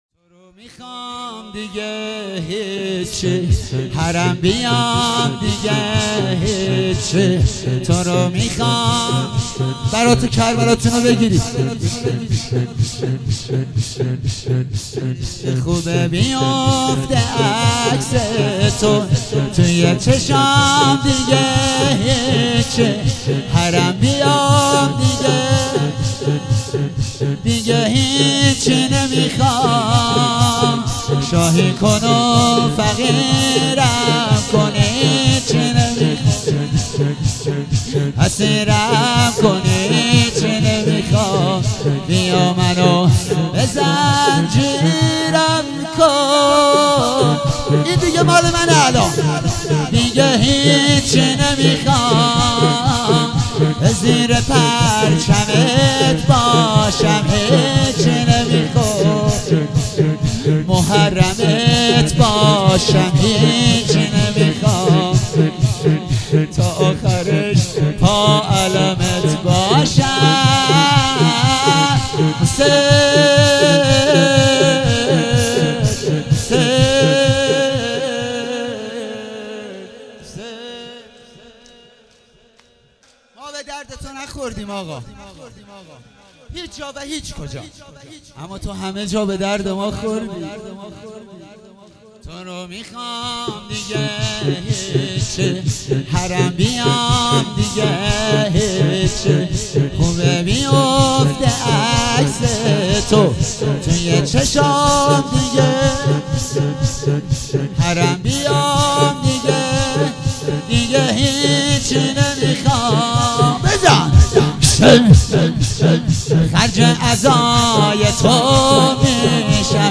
شور - تورو میخوام دیگه هیچی